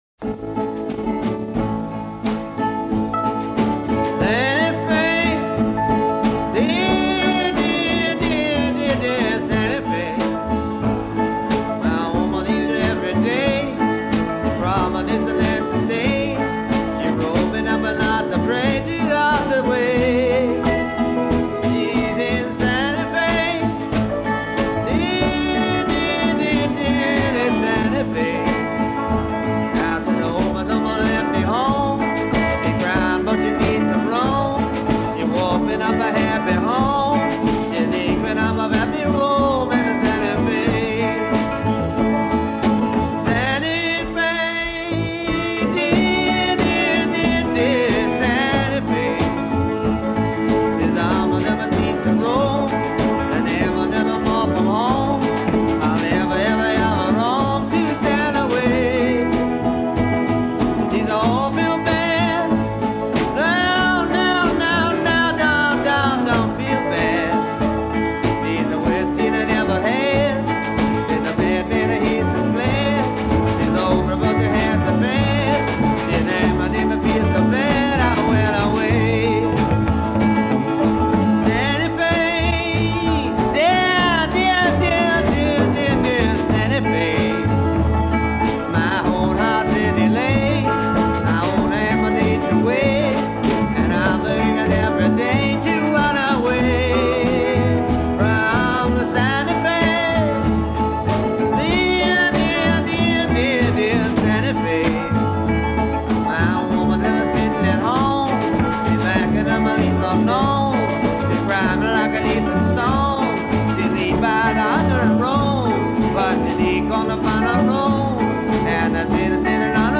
an outtake